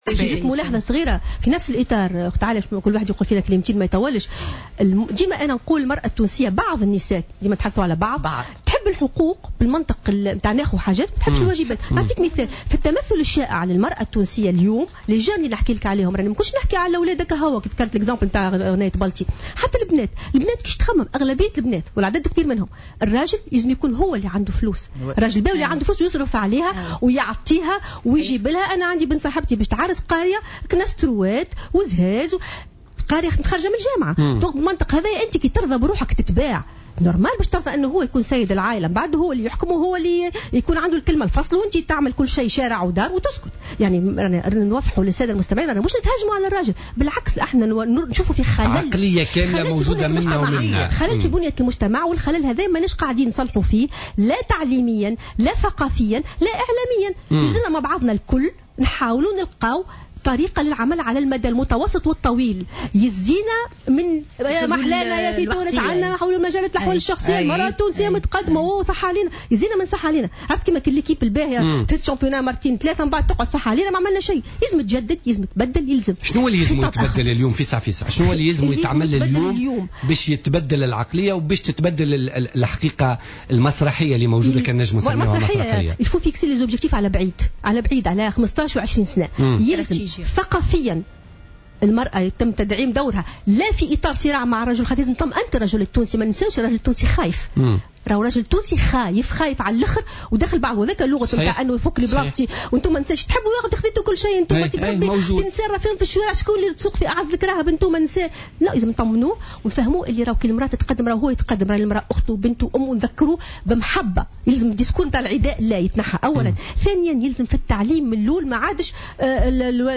وقالت في مداخلة لها في برنامج بوليتيكا على "جوهرة أف أم" اليوم الأربعاء ان الرجل التونسي ينظر للمراة بريبة وتخوف متوهما أن الامتيازات الاجتماعية التي تمكنت من بلوغها على مستوى الحقوق والقوانين قد تهدد مكانته بالمجتمع.